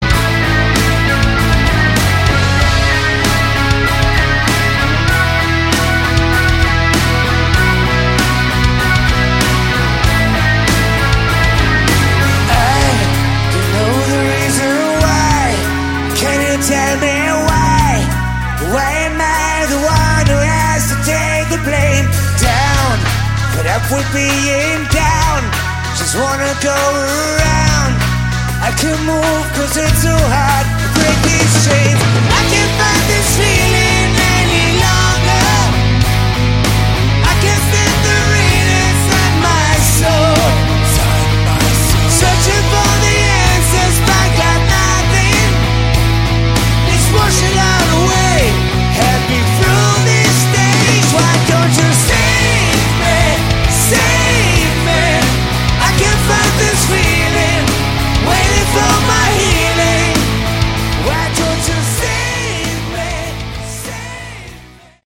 Category: Hard Rock
lead vocals, guitar
bass, vocals
keyboards, vocals
drums